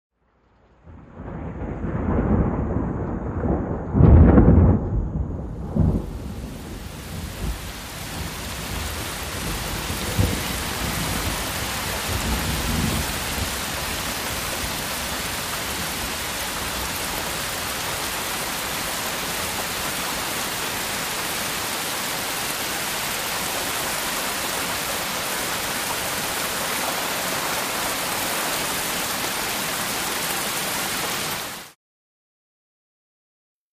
ThunderCrackHeavy PE505601
Thunder Crack With Heavy Rainfall And Rural Ambience.